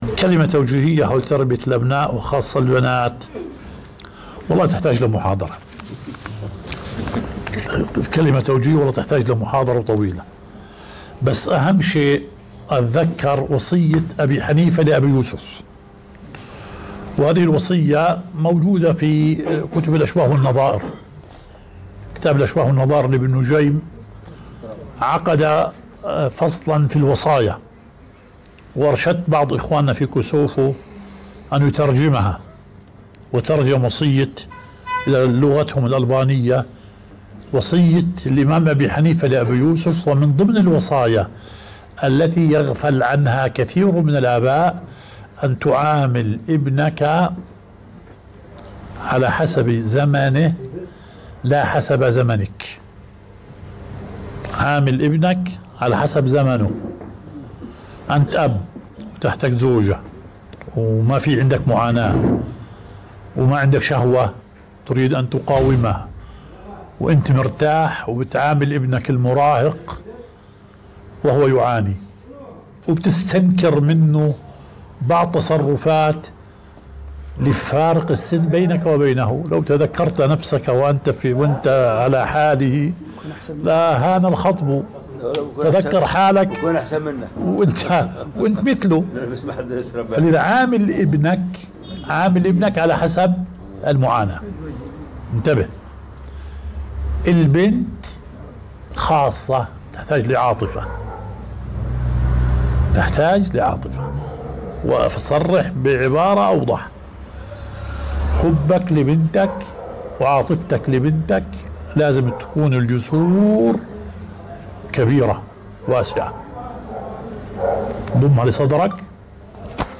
مجلس سؤال وجواب